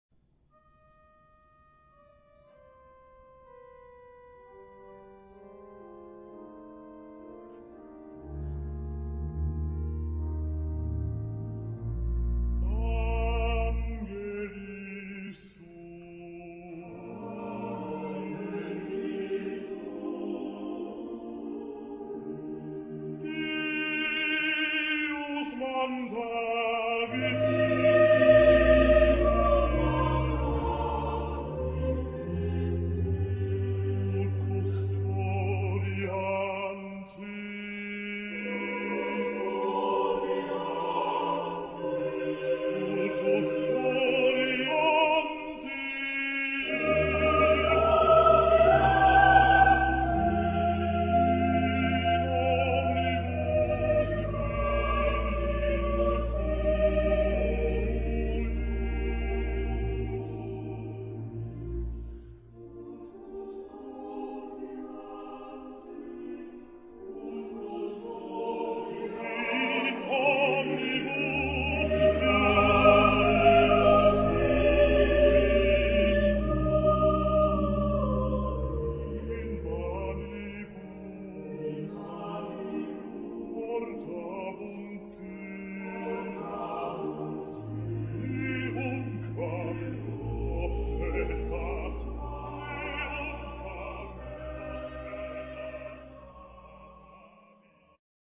Genre-Style-Form: Romantic ; Sacred ; Motet
Type of Choir: SATB  (4 mixed voices )
Soloist(s): Baryton (1)  (1 soloist(s))
Instruments: Organ (1) ; Cello (1)
Tonality: E flat major
sung by Kammerchor Stuttgart conducted by Frieder Bernius